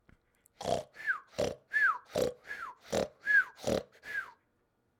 Fast Snore
big cartoon fast snore snoring sound effect free sound royalty free Movies & TV